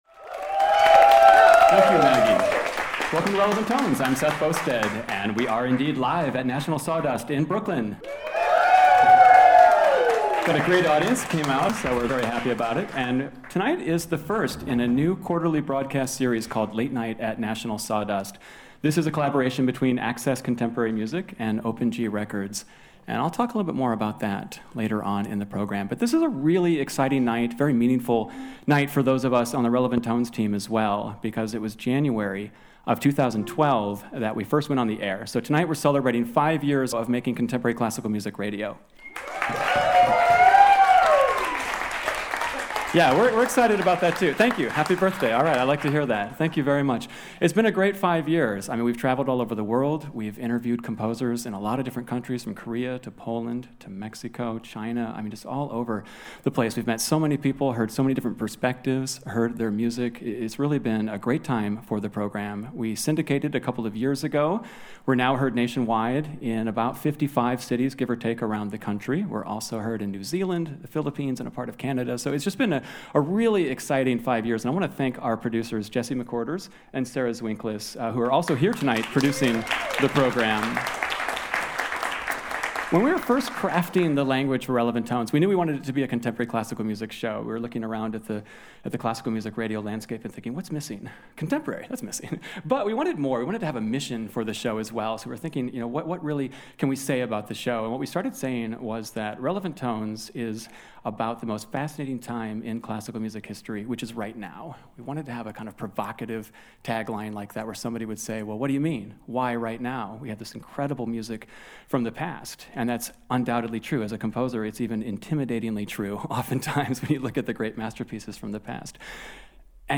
Podcast and live radio collide in an evening of music inspired by fractals, dynamic systems, feedback loops and nature.
a string quartet
gorgeously abrasive
haunting
soprano
clarinetist